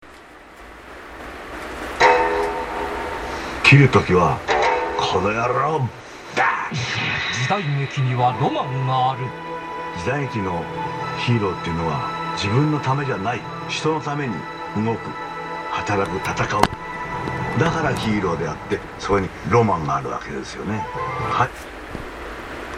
スカイパーフェクトTV時代劇専門チャンネルでの里見浩太朗さんの発言
スカイパーフェクトTV時代劇専門チャンネルで「篤姫」第四回が放送された時に、「篤姫」開始の直前に放送された里見浩太朗さんの発言を録音しました。